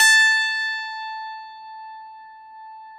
53s-pno16-A3.aif